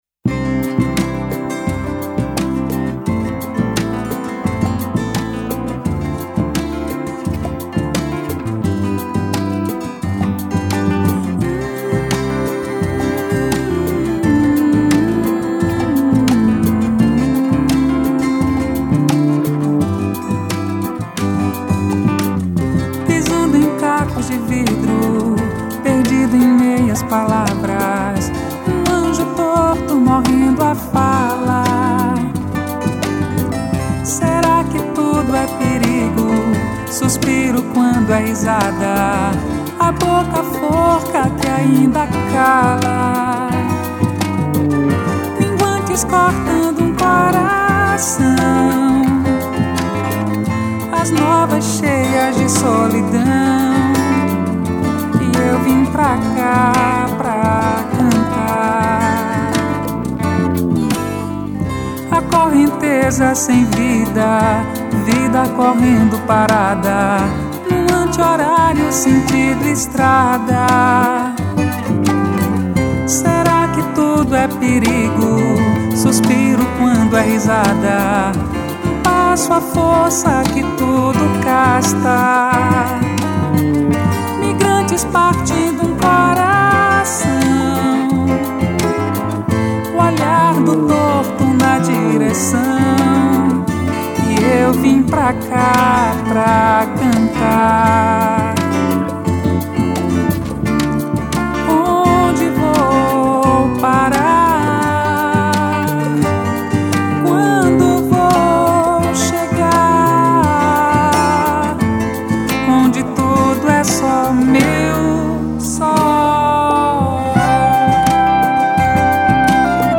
833   06:17:00   Faixa:     Mpb
Violao Acústico 6, Voz, Percussão
Clarinete, Saxofone Tenor